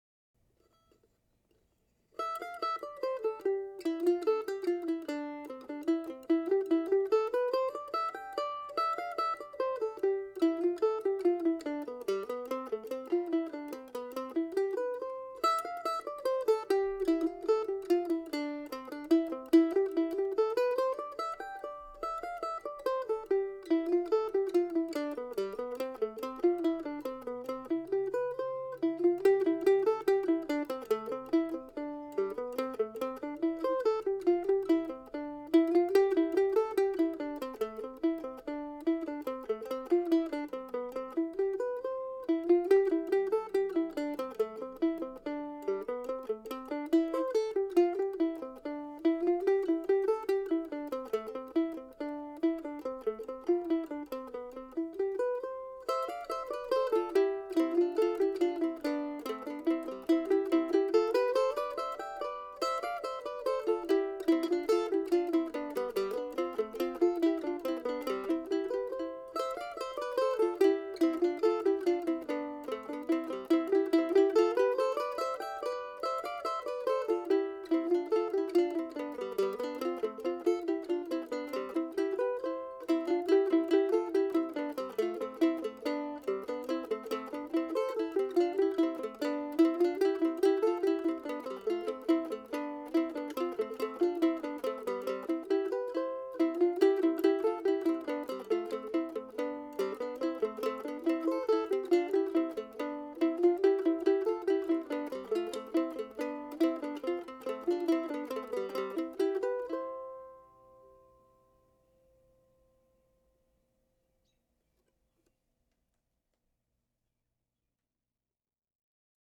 August 28, 2005 Duo August 28, 2005 Duo ( mp3 ) ( pdf ) Here's another tune from 2005 that I've turned into a duo.